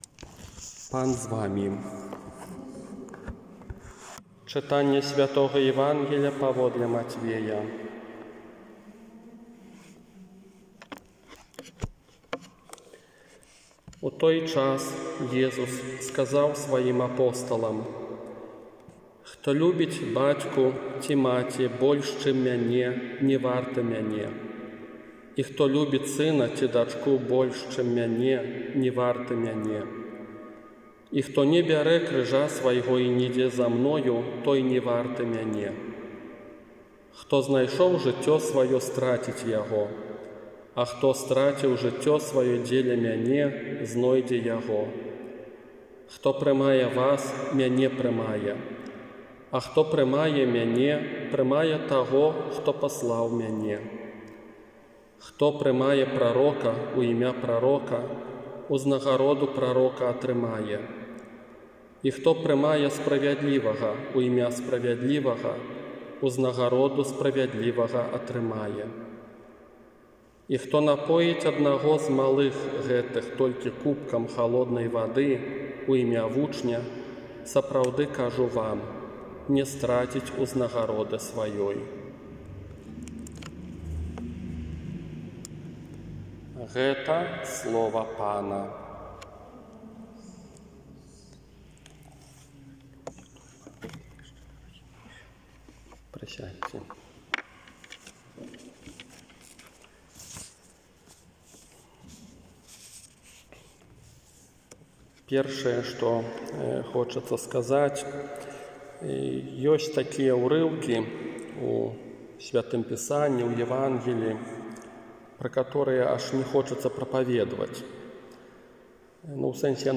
ОРША - ПАРАФІЯ СВЯТОГА ЯЗЭПА
Казанне не трынаццатую звычайную нядзелю 28 чэрвеня 2020 года